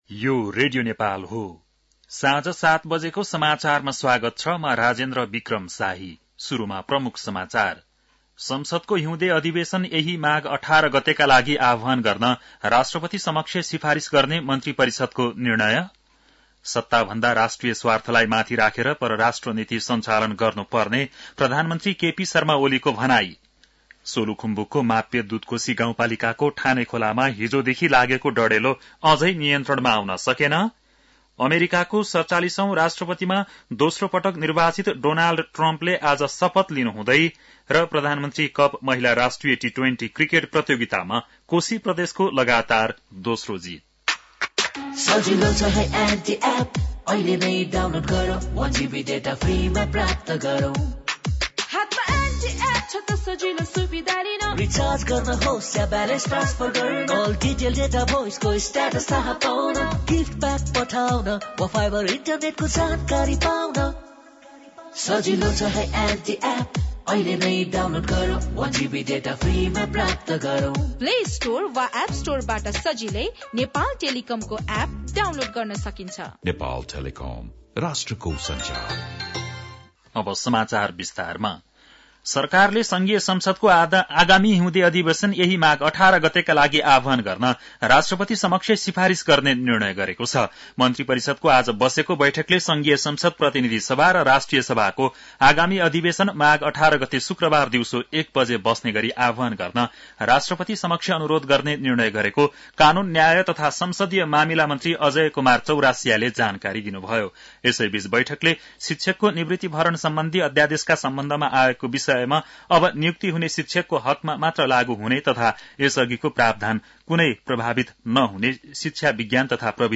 बेलुकी ७ बजेको नेपाली समाचार : ८ माघ , २०८१
7-pm-nepali-news-10-07.mp3